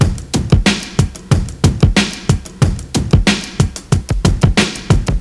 • 92 Bpm Modern Rap Drum Groove F Key.wav
Free breakbeat sample - kick tuned to the F note. Loudest frequency: 1263Hz
92-bpm-modern-rap-drum-groove-f-key-pig.wav